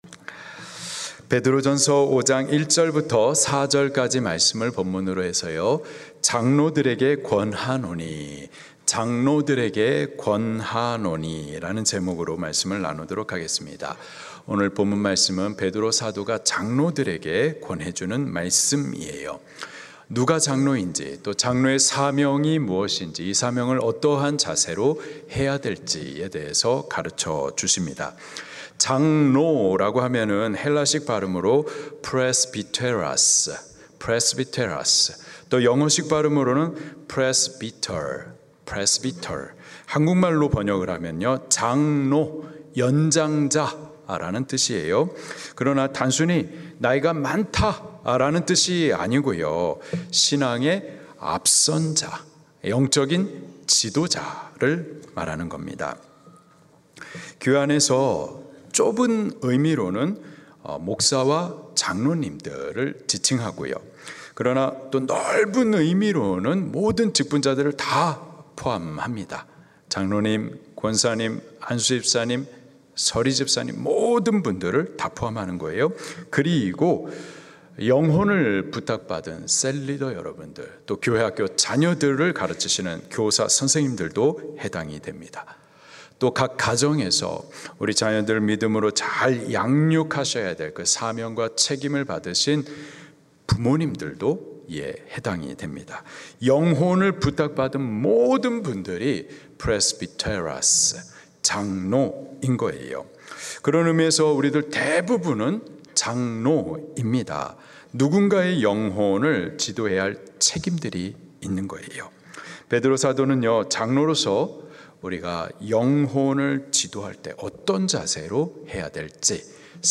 설교